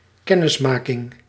Ääntäminen
Vaihtoehtoiset kirjoitusmuodot (vanhentunut) acquaintaunce Synonyymit knowledge familiarity fellowship intimacy friend Ääntäminen US : IPA : [ʌˈkwen.tɛns] RP : IPA : /əˈkweɪntəns/ US : IPA : /ʌˈkweɪn.təns/